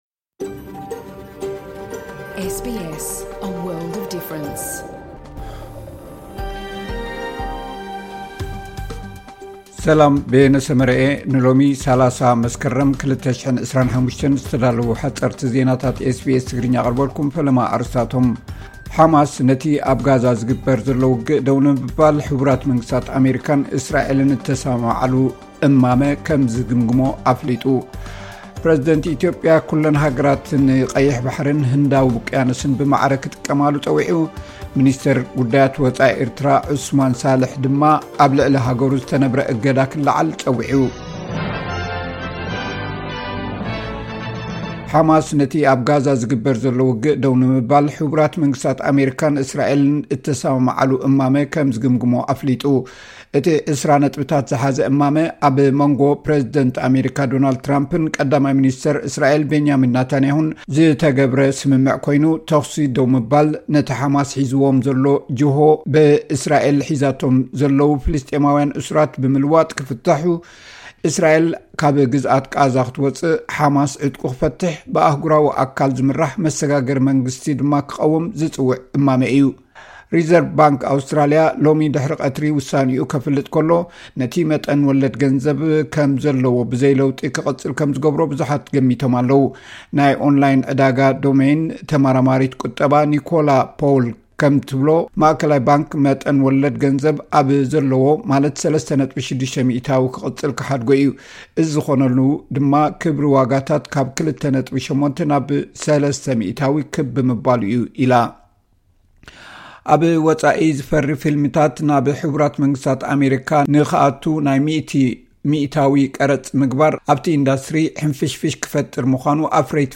ፕ/ት ኢትዮጵያ፡ ኩለን ሃገራት ንቀይሕ ባሕሪን ህንዳዊ ውቅያኖስን ብማዕረ ክጥቀማሉ ጸዊዑ፡ ሚ/ር ጉዳያት ወጻኢ ኤርትራ ድማ ኣብ ልዕሊ ሃገሩ ዝተነብረ እገዳ ክለዓል ጸዊዑ።.ሓጸርቲ ዜናታት ኤስ ቢ አስ ትግርኛ